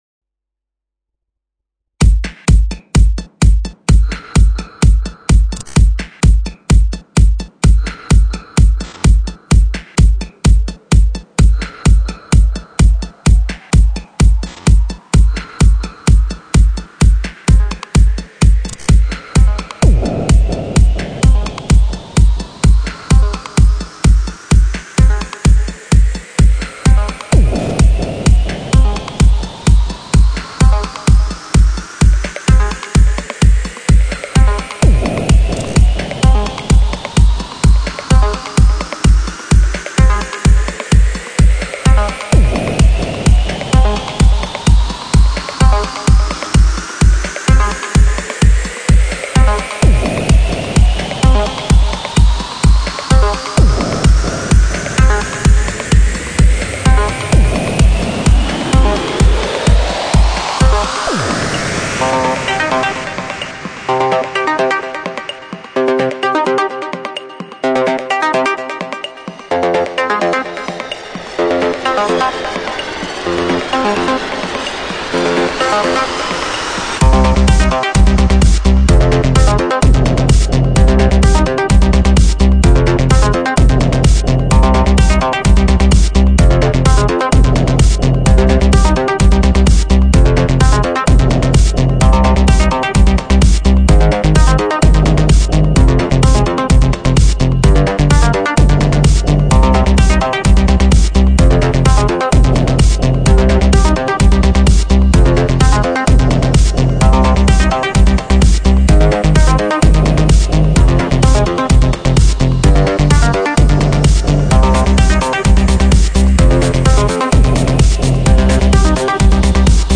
Главная » Музыка » Dance mix